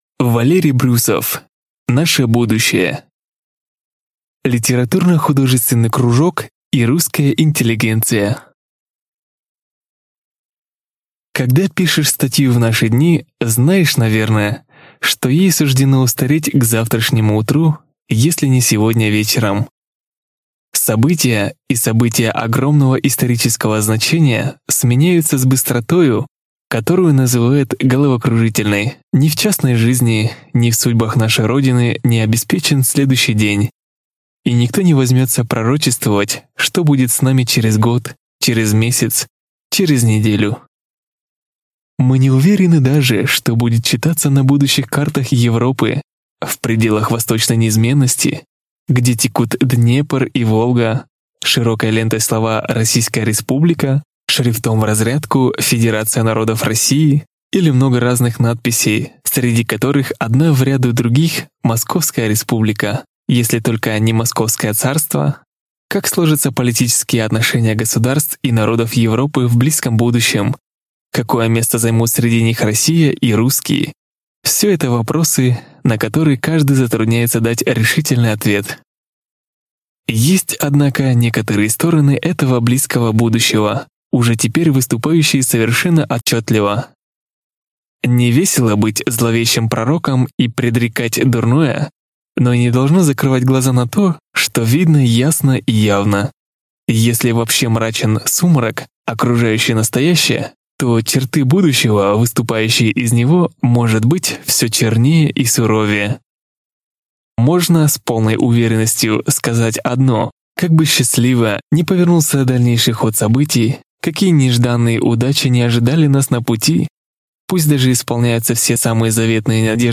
Аудиокнига Наше будущее | Библиотека аудиокниг